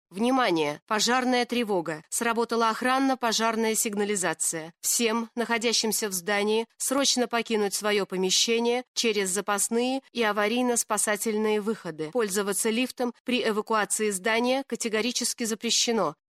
В комплекте с магнитофоном идет SD-карта объемом 8 Гб со стандартным тревожным сообщением.